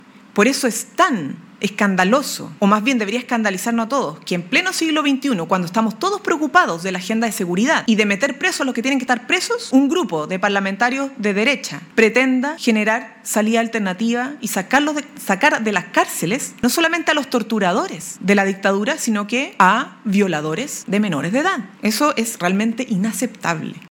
CUNA-VOCERIA-.mp3